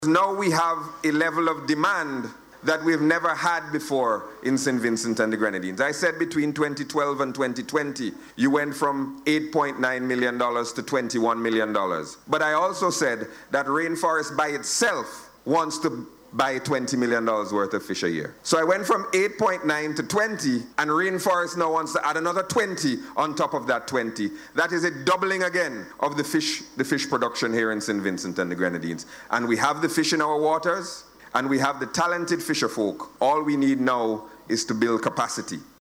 Minister Gonsalves made the statement while delivering remarks at the recent opening of Rainforest Seafoods at Calliaqua.